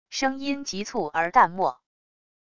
声音急促而淡漠wav音频